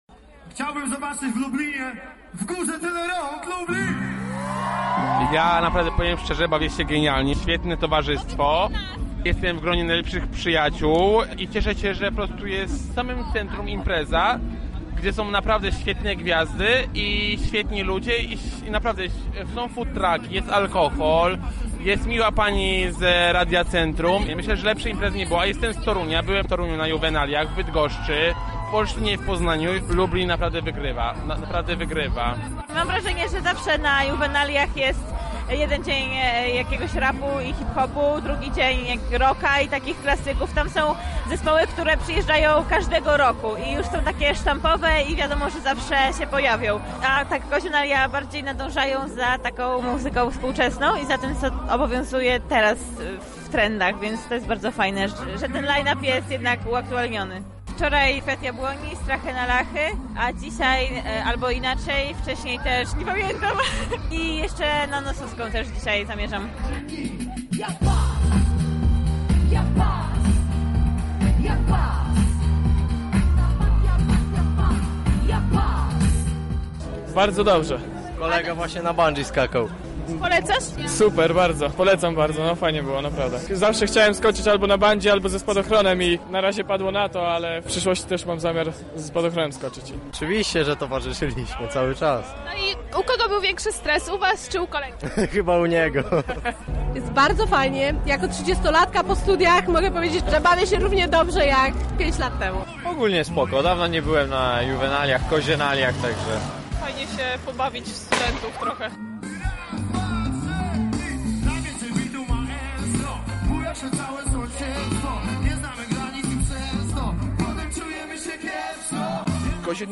Przygotowaliśmy dla Was relację: